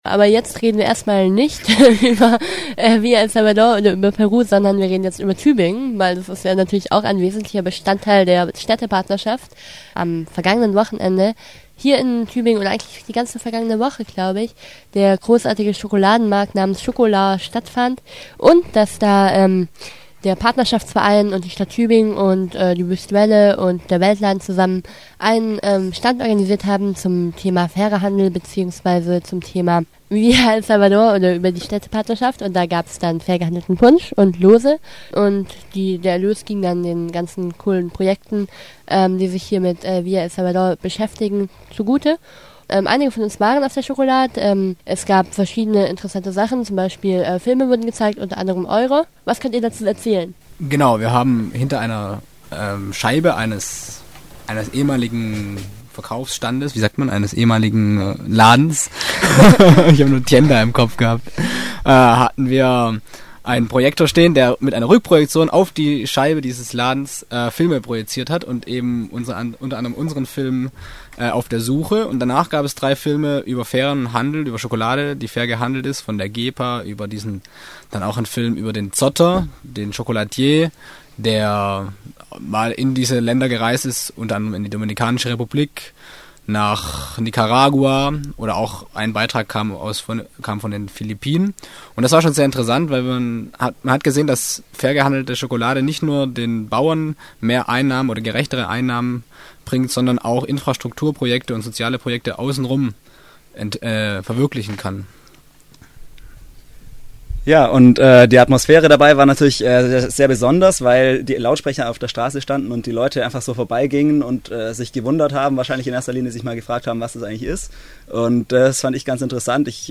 m Studio hatten wir noch eine kleine Diskussion über die ChocolArt:
36777_diskussion-ChocolArt.mp3